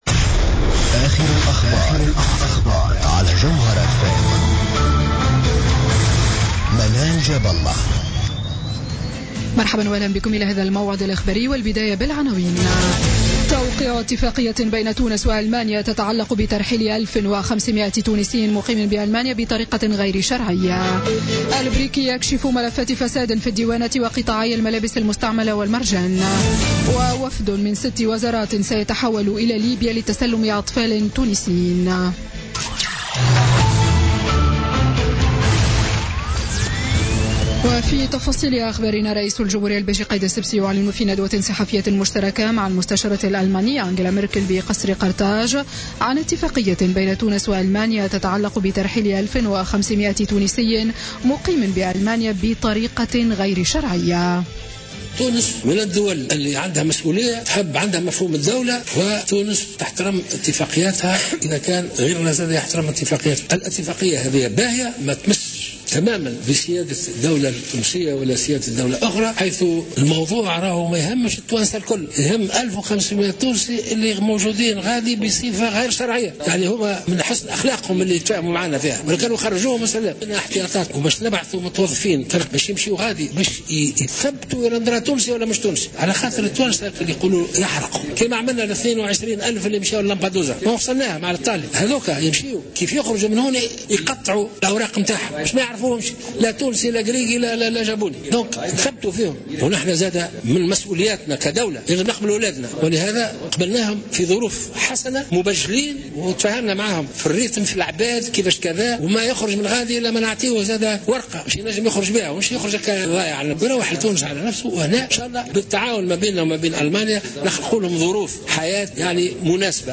نشرة أخبار السابعة مساء ليوم الجمعة 3 مارس 2017